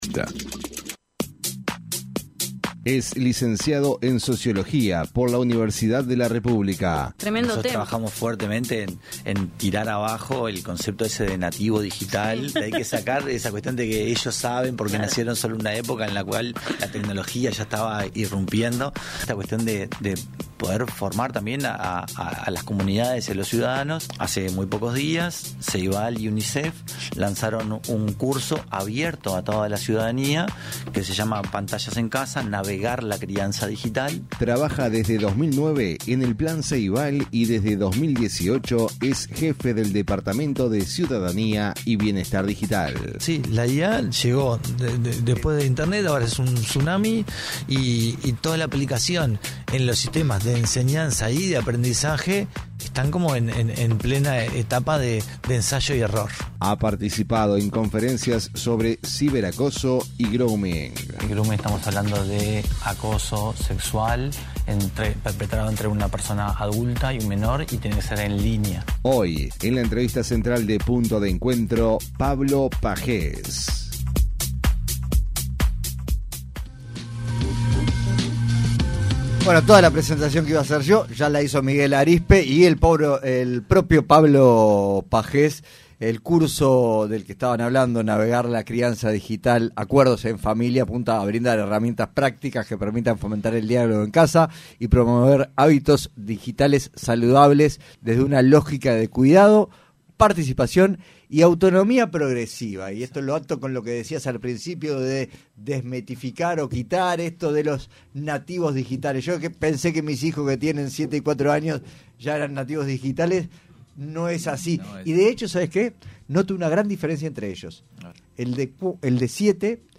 se refirió en entrevista con Punto de Encuentro al incidente de ciberseguridad de Plan Ceibal.